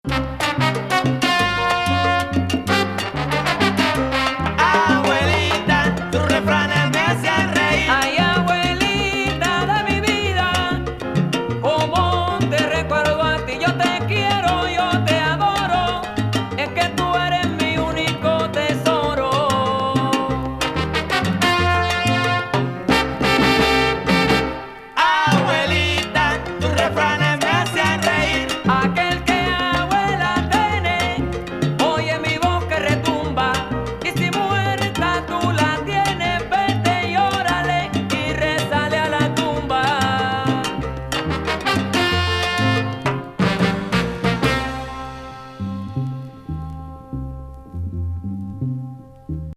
ルーディ&ダーティなサルサ・ナンバー